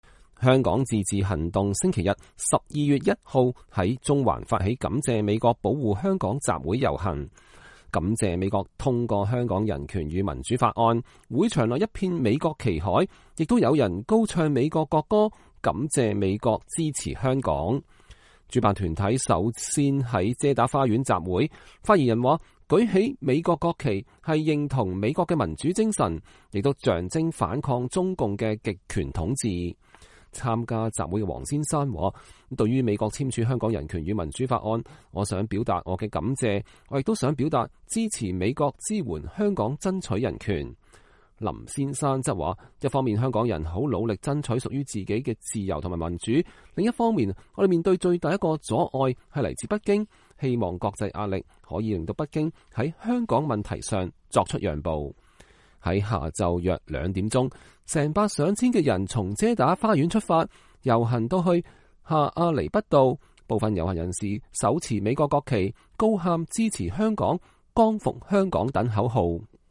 會場內一片美國旗海，也有人高唱美國國歌，感謝美國對香港的支持。
在下午約兩點，成百上千的人從遮打花園出發，遊行至下亞厘畢道，部分遊行人士手持美國國旗，高喊“支持香港” “光復香港”等口號。